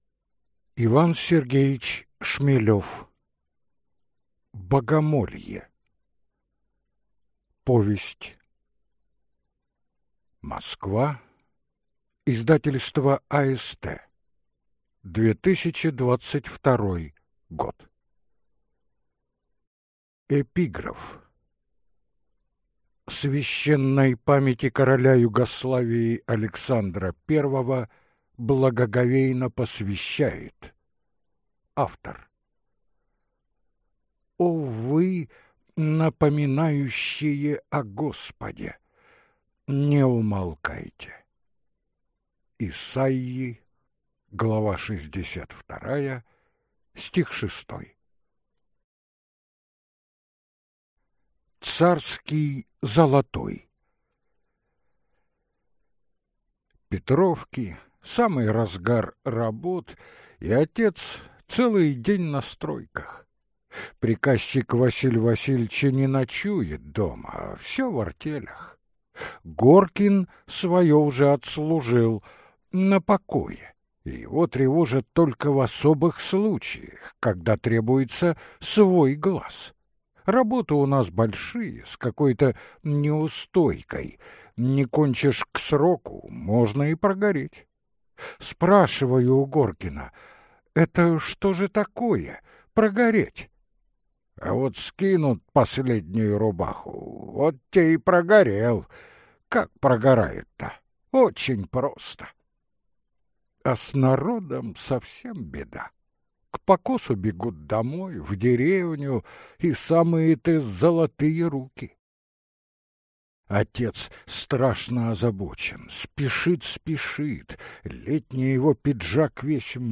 Аудиокнига Богомолье. Старый Валаам | Библиотека аудиокниг